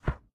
Minecraft Version Minecraft Version latest Latest Release | Latest Snapshot latest / assets / minecraft / sounds / item / book / close_put1.ogg Compare With Compare With Latest Release | Latest Snapshot
close_put1.ogg